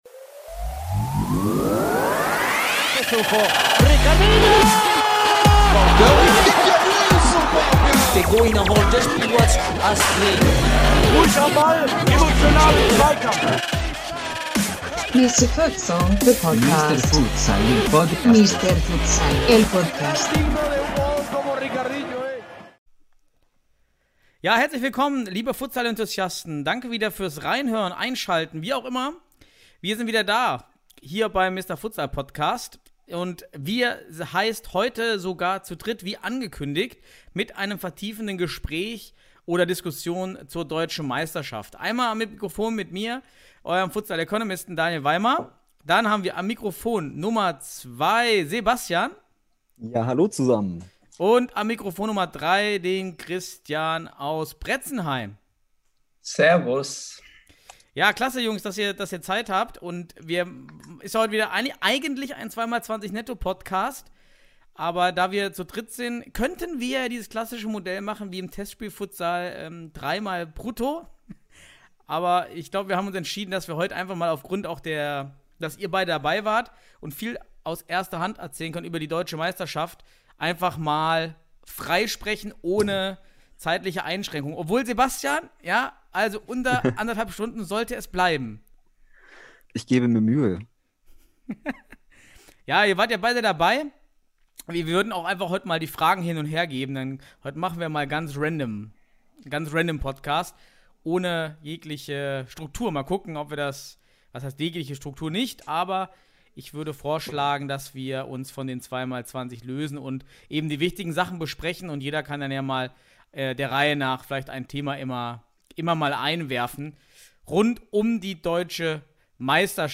Wie in Folge 48 angekündigt, haben wir in dieser Folge zu dritt ausführlich über die Deutschen Meisterschaft 2020 als auch über die aktuelle Nominierung der Futsal-Nationalmannschaft diskutiert.